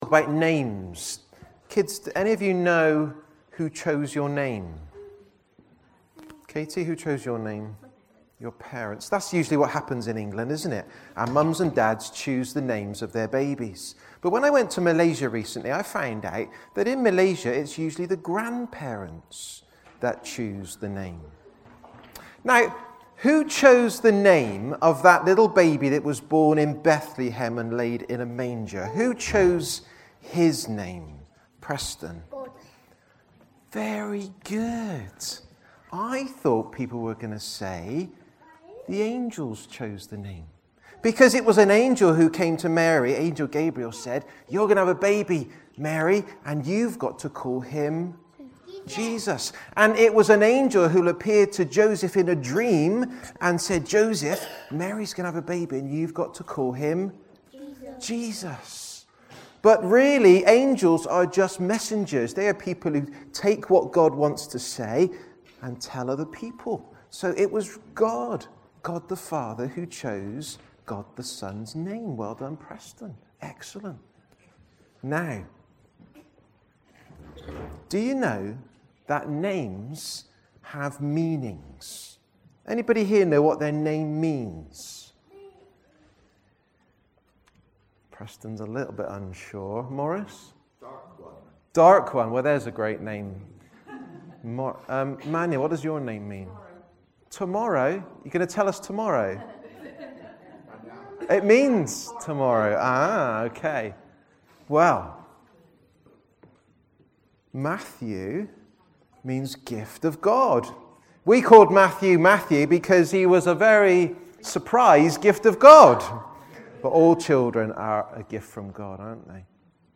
Nativity Service